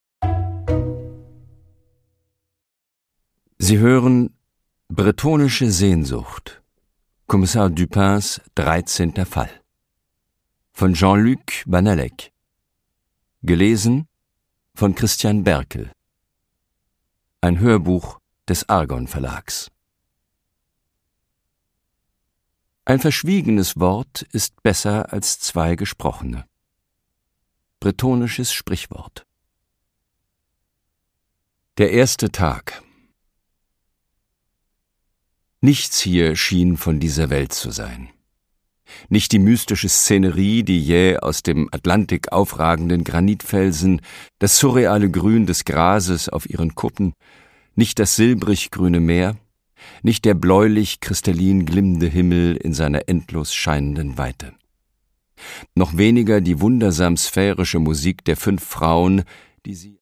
Produkttyp: Hörbuch-Download
Gelesen von: Christian Berkel